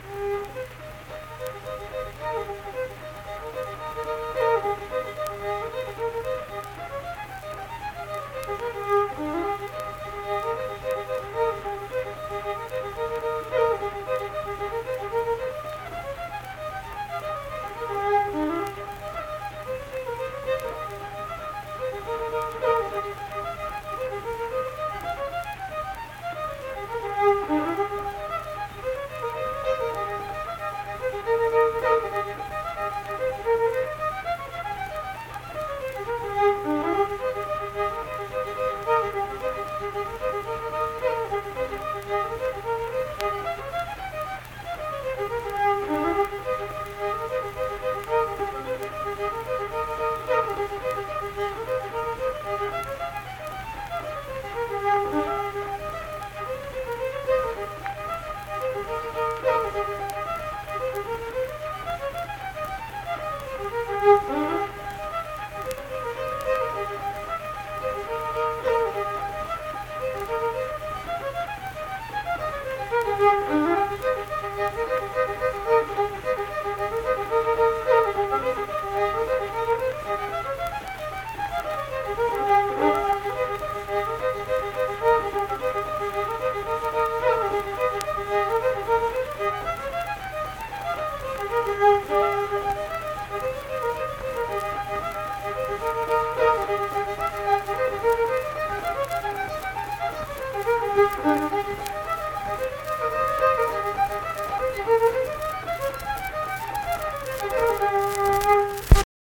Unaccompanied fiddle music
Folk music--West Virginia, Ballads
Instrumental Music
Fiddle